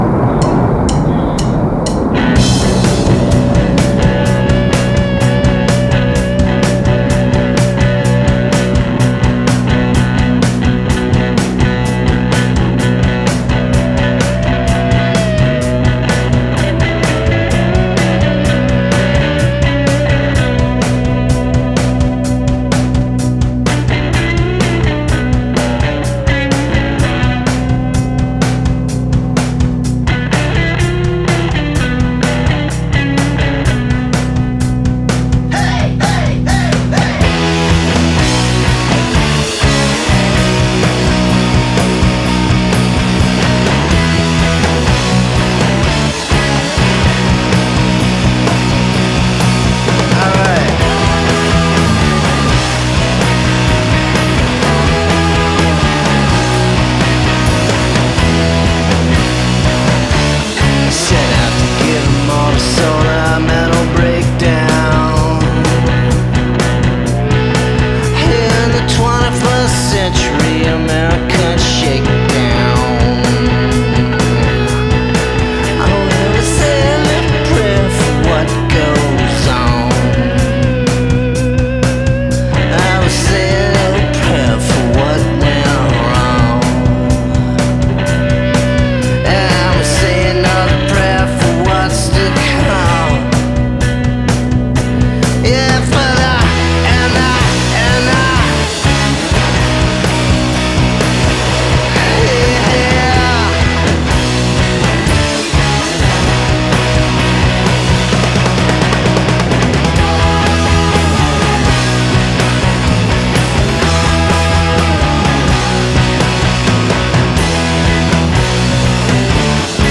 Indie rock, southern gothic, a little krautrock and a punk